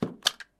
Poner un sello de caucho 1
Sonidos: Acciones humanas
Sonidos: Oficina